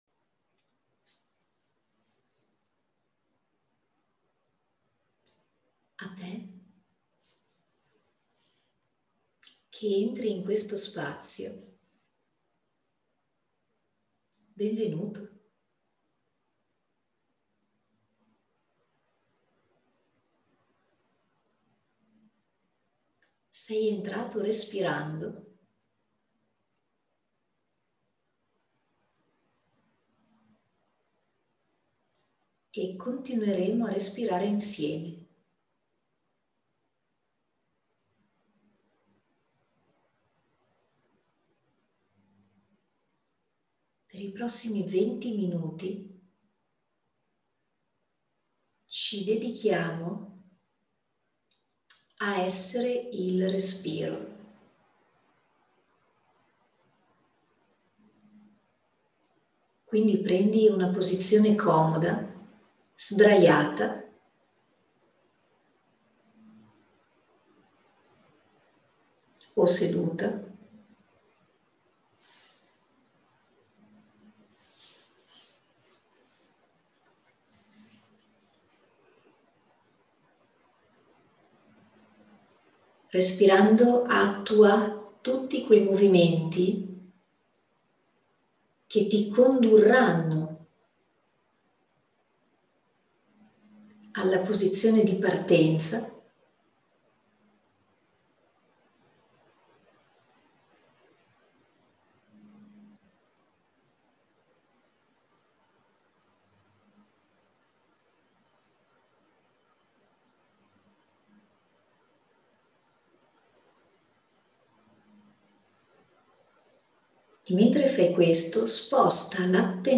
Una meditazione trasformativa: i cicli respiratori hanno Spazi a noi sconosciuti, dentro e fuori.